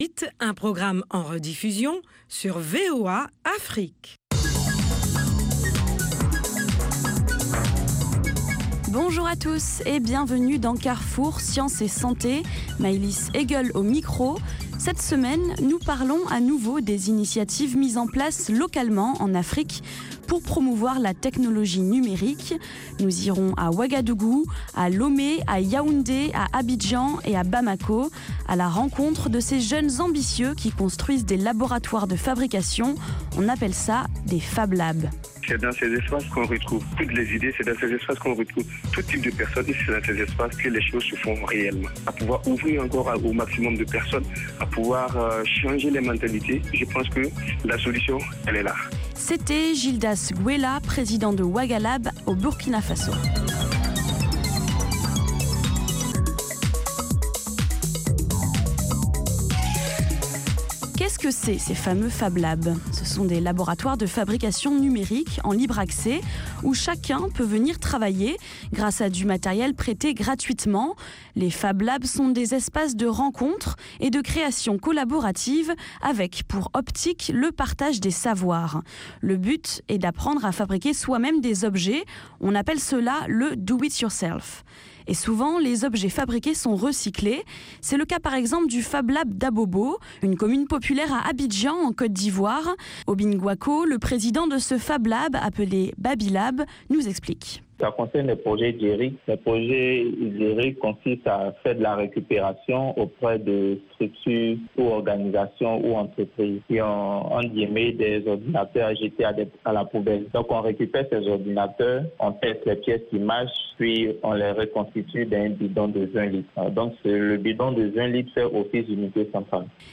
Carrefour Sciences et Santé vous offre sur la VOA les dernières découvertes en matière de technologie et de recherche médicale. Il vous propose aussi des reportages sur le terrain concernant les maladies endémiques du continent africain.